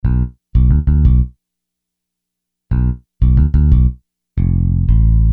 Hiphop music bass loop - 90bpm 70